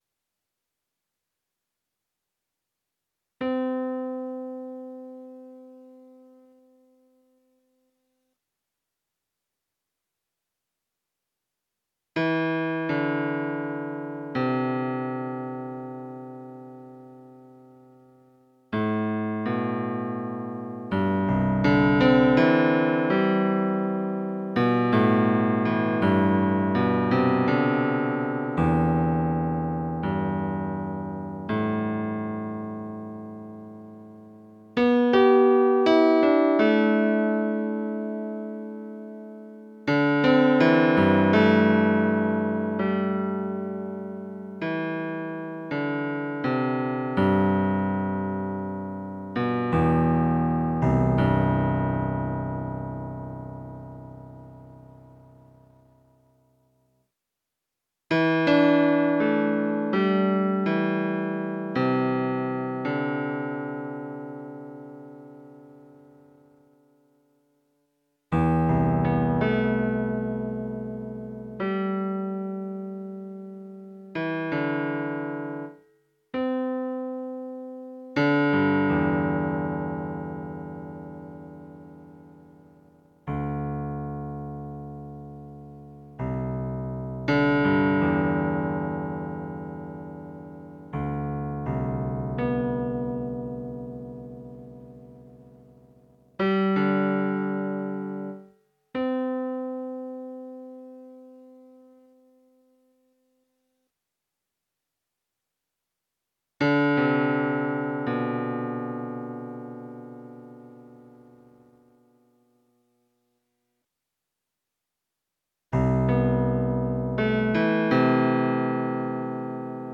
Jazz radio